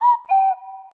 Звуки телефона Huawei
Вы можете слушать онлайн и скачивать стандартные рингтоны, мелодии будильника, оповещения о сообщениях и системные сигналы.